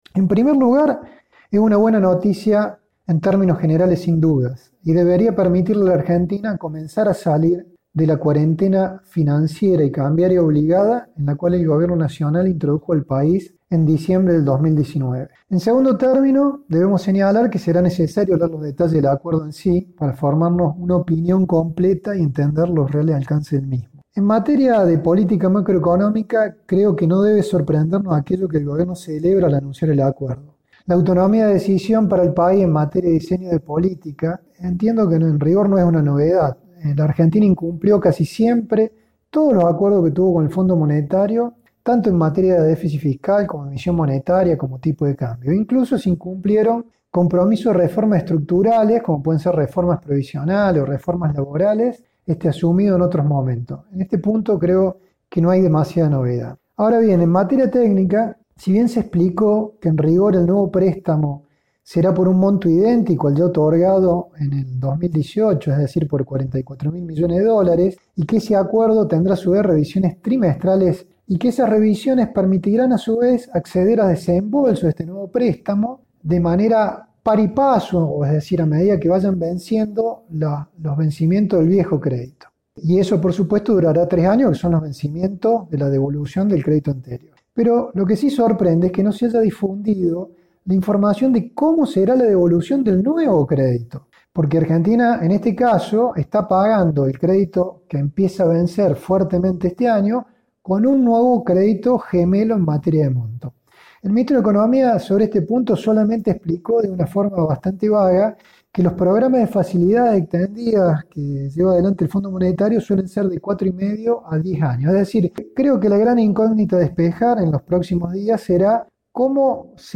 dialogó con Cadena 3 respecto al acuerdo con el Fondo Monetario Internacional que anunció este viernes el presidente Alberto Fernández.